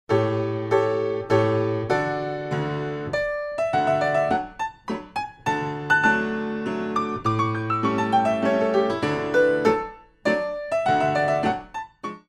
Hand Clap with 3/4 Music